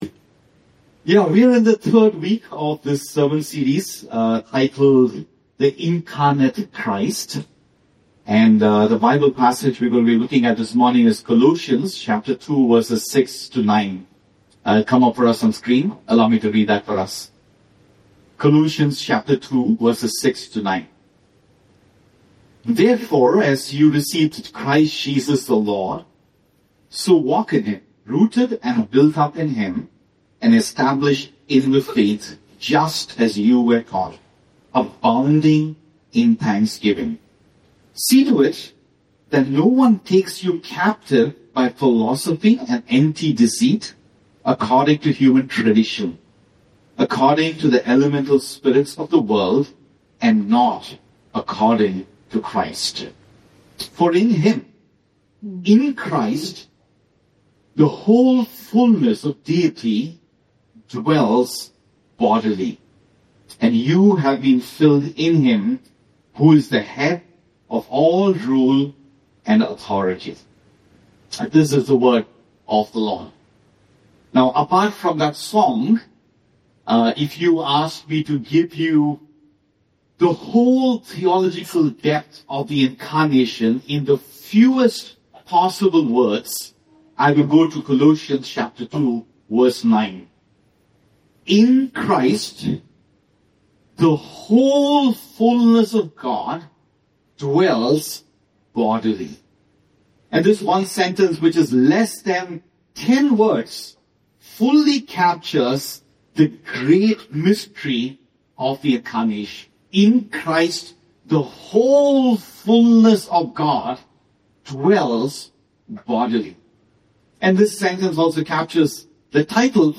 A message from the series "The Incarnate Christ."